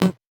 Index of /musicradar/8-bit-bonanza-samples/VocoBit Hits
CS_VocoBitC_Hit-08.wav